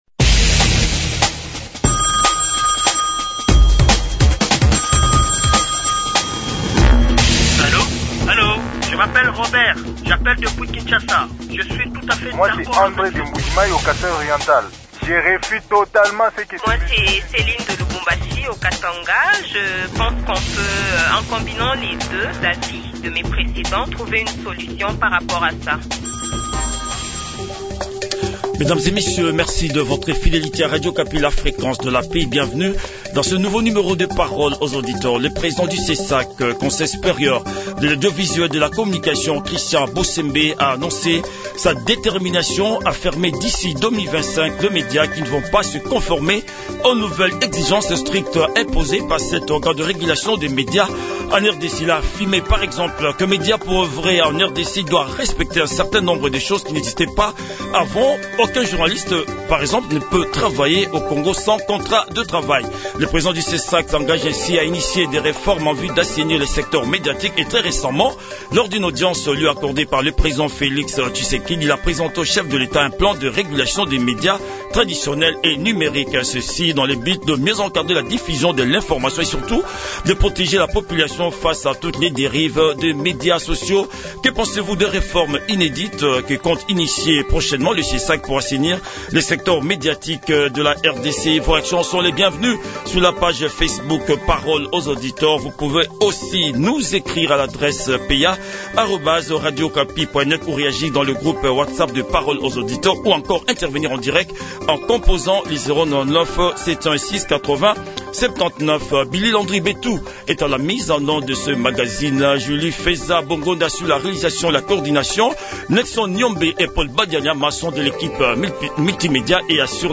Les auditeurs ont débattu avec Christian Bosembe, président du Conseil Supérieur de l'Audiovisuel et de la Communication (CSAC).